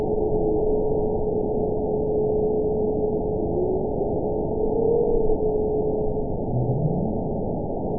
event 921199 date 05/01/24 time 06:22:02 GMT (1 year, 1 month ago) score 9.38 location TSS-AB10 detected by nrw target species NRW annotations +NRW Spectrogram: Frequency (kHz) vs. Time (s) audio not available .wav